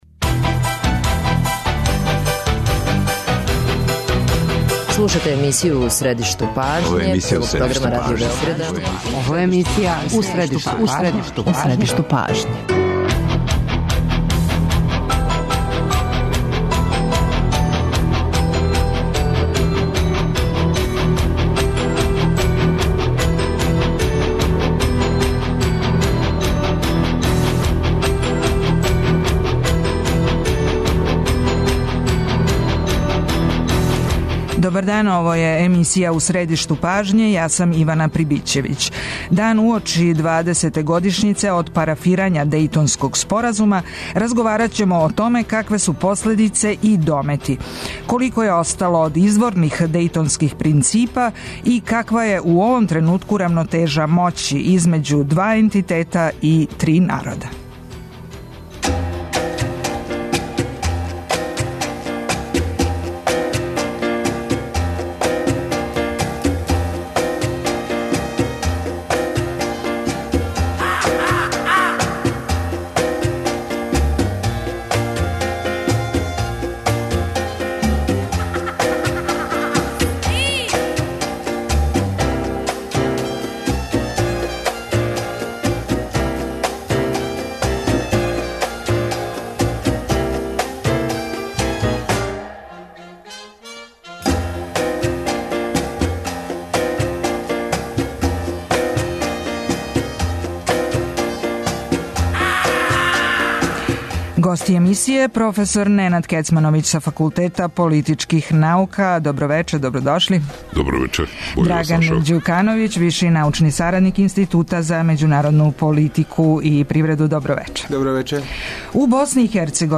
Аудио подкаст Радио Београд 1